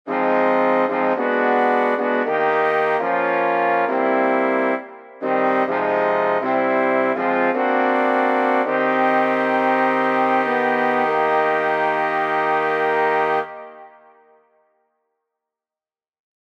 Key written in: E♭ Major
How many parts: 4
Type: Barbershop
All Parts mix: